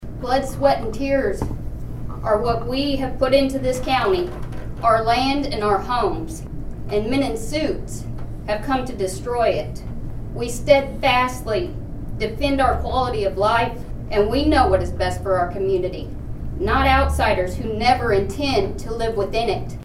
Nowata Residents Oppose Wind Farms at Commissioner Meeting
Here is what one concerned local had to say.